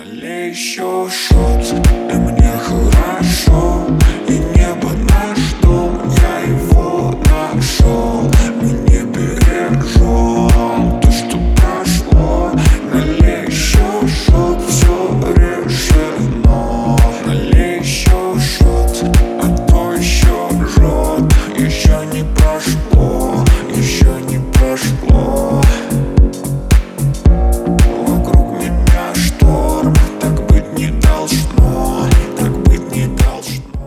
• Качество: 320, Stereo
русский рэп